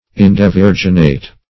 Meaning of indevirginate. indevirginate synonyms, pronunciation, spelling and more from Free Dictionary.
Search Result for " indevirginate" : The Collaborative International Dictionary of English v.0.48: Indevirginate \In`de*vir"gin*ate\, a. [See In- not, Devirginate .]